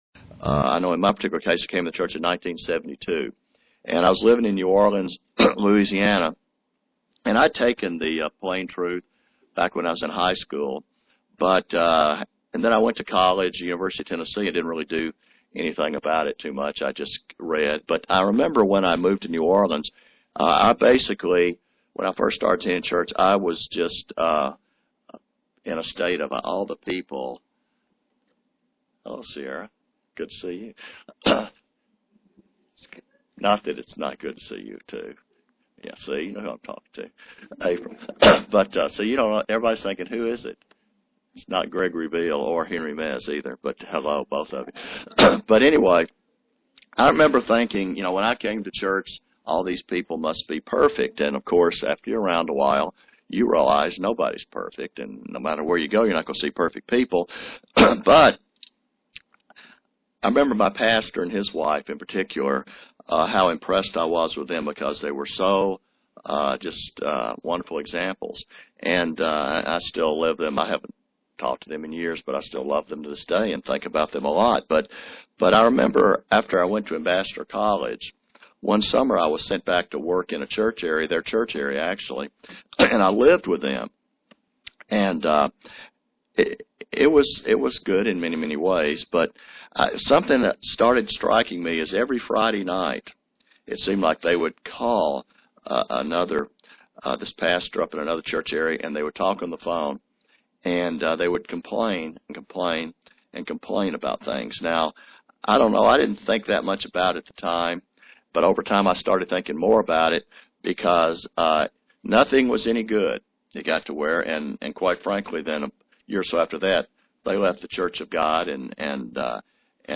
Given in Knoxville, TN London, KY
Print We can develop a root of bitterness if not careful in our Christian walk UCG Sermon Studying the bible?